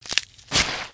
点燃火柴－YS070517.wav
通用动作/01人物/02普通动作类/点燃火柴－YS070517.wav